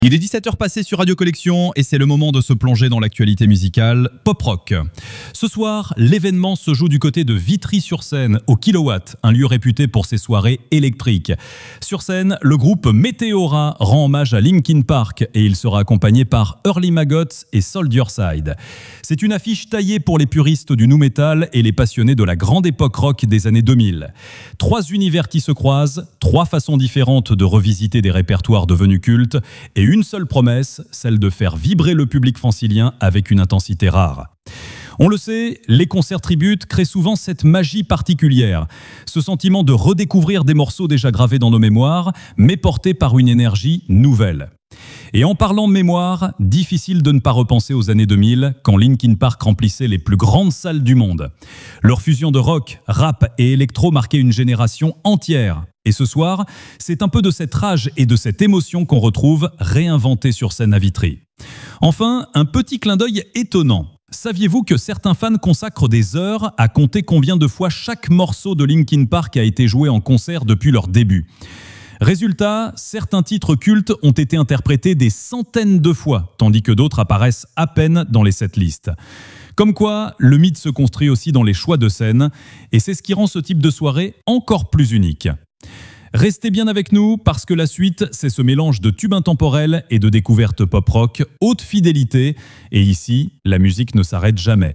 Vous écoutez la chronique Pop Rock de Radio Collection, la webradio gratuite et sans pub qui diffuse les plus grands classiques et les nouveautés en qualité Hi-Fi.